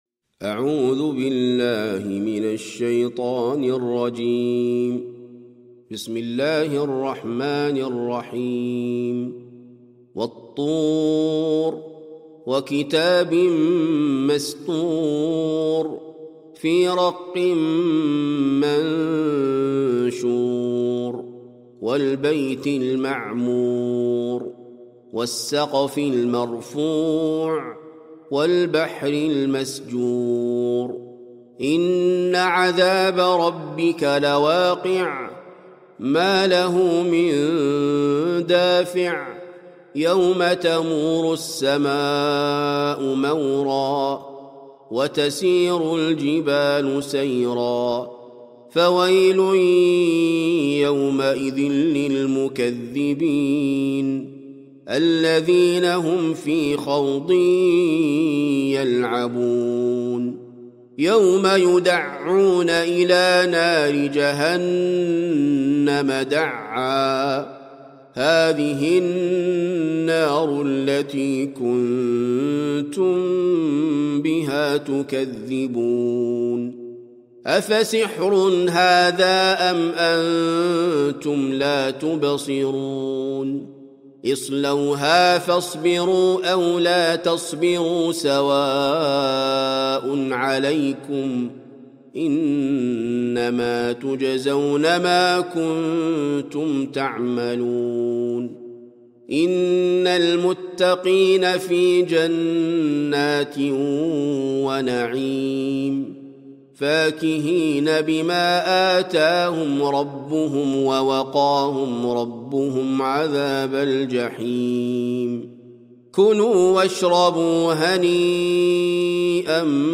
سورة الطور - المصحف المرتل (برواية حفص عن عاصم)